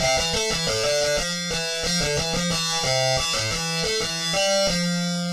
吉他合成器1
Tag: 90 bpm Hip Hop Loops Guitar Electric Loops 918.92 KB wav Key : Unknown